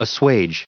added pronounciation and merriam webster audio
85_assuage.ogg